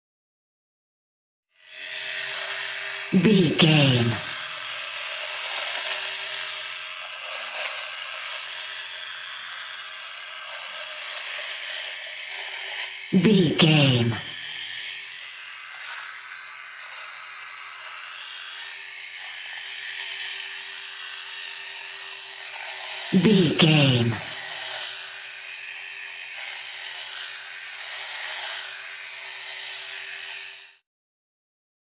Electric shaver small shaving
Sound Effects